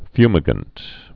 (fymĭ-gənt)